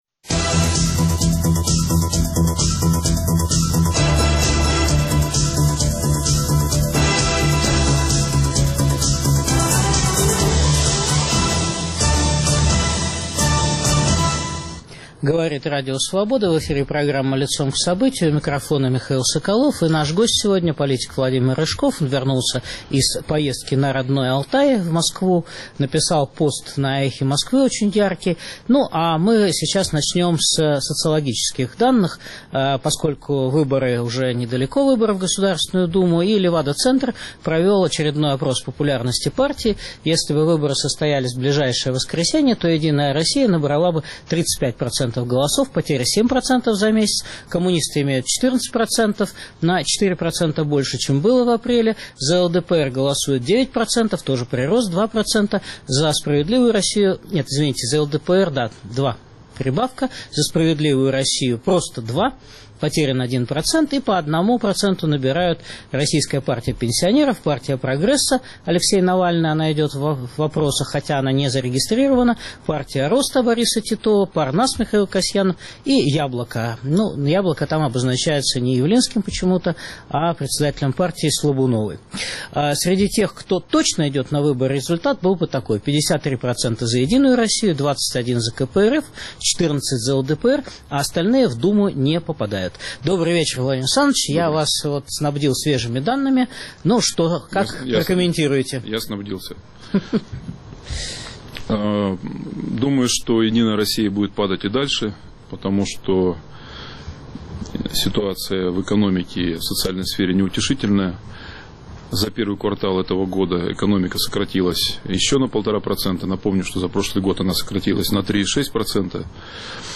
Чего хотят граждане России? О предвыборных настроениях беседуем с Владимиром Рыжковым